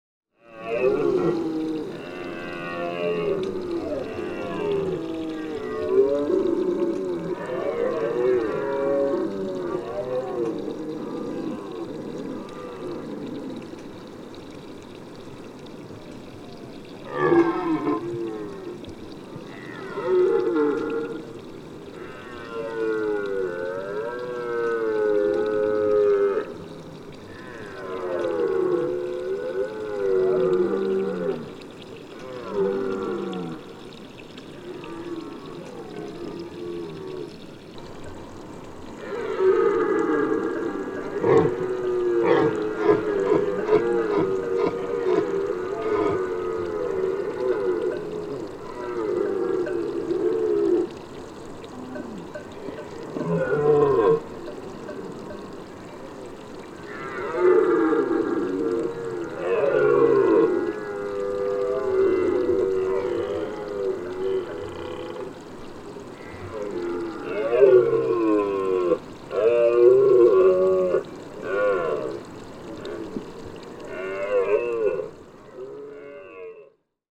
Pour enregistrer les sons du brame, j’ai utilisé un microphone parabolique très sensible, veillant à garder une distance de sécurité pour éviter de perturber les rituels d’accouplement.
Brame-du-cerf-2.mp3